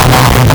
Player_Glitch [19].wav